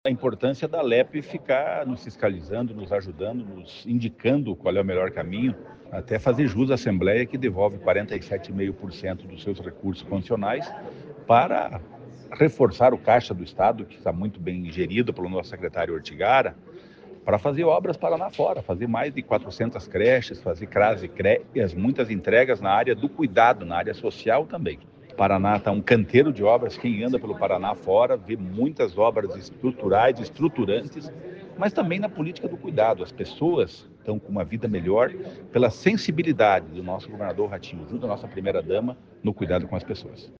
Sonora do secretário do Desenvolvimento Social e Família, Rogério Carboni, sobre a apresentação do PPA na Alep